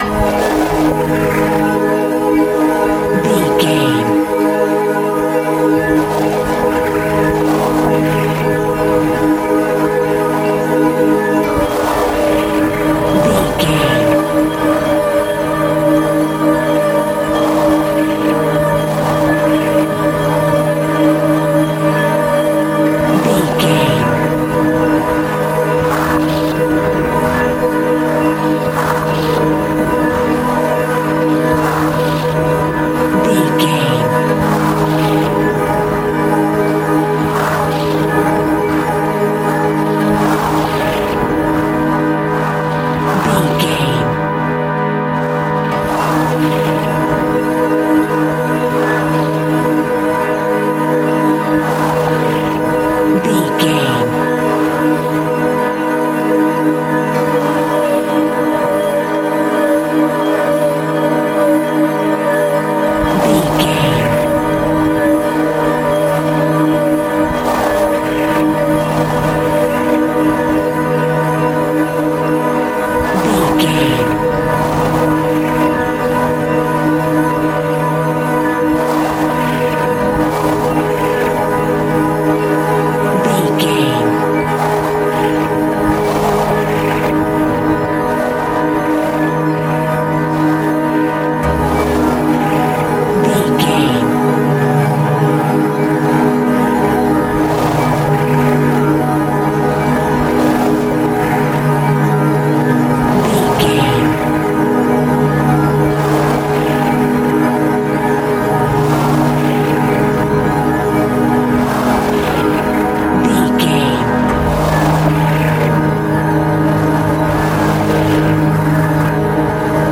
In-crescendo
Aeolian/Minor
ominous
suspense
eerie
Horror Ambience
Synth Pads
Synth Ambience